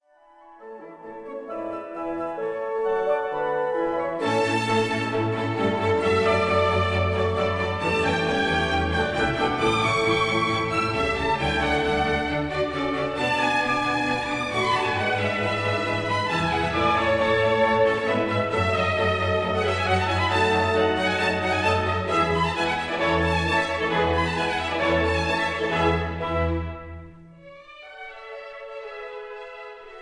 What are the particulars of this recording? This is a 1960 stereo recording